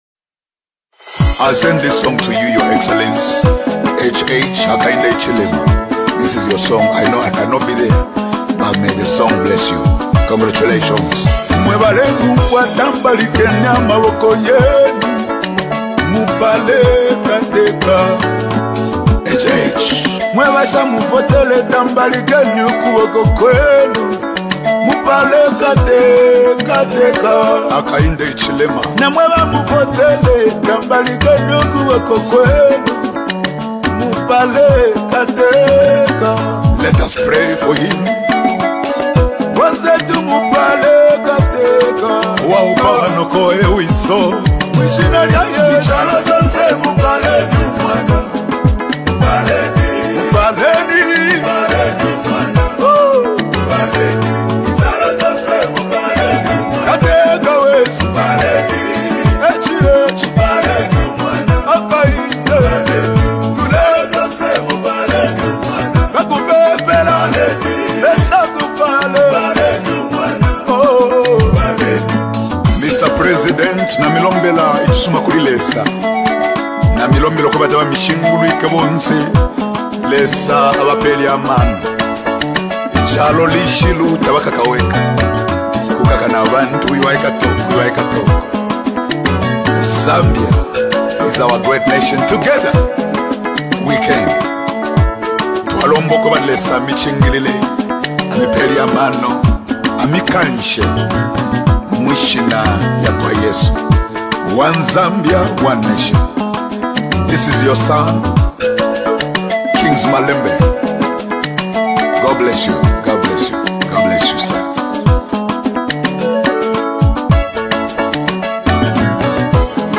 a powerful and energizing song
a blend of inspirational lyrics and vibrant rhythms